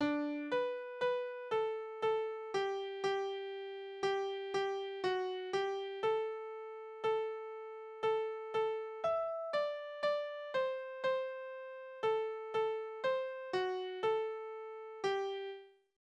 Tanzverse: Verschiedene Tänze Guten Tag, Herr Gärtnersmann!
Tonart: G-Dur Taktart: C (4/4) Tonumfang: große None Besetzung: vokal Externe Links: Sprache: hochdeutsch eingesendet aus Strippow (29.04.1933) Fragen, Hinweise, Kritik?